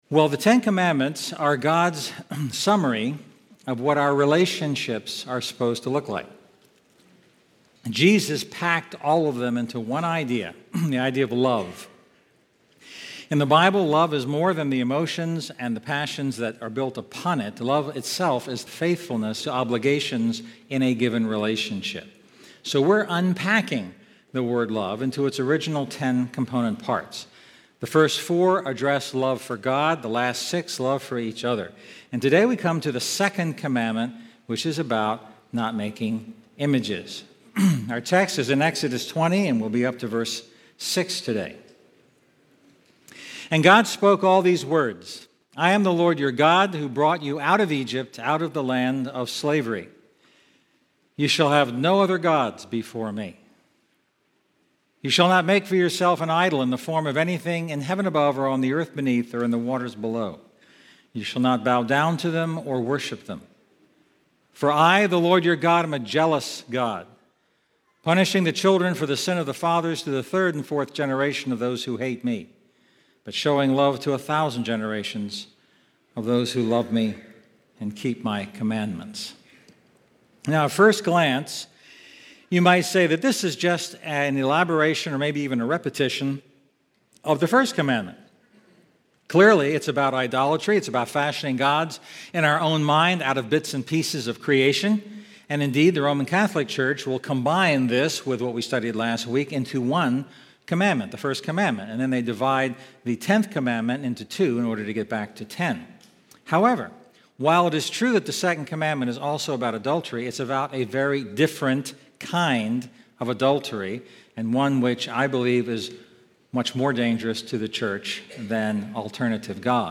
Sermons – GrowthGround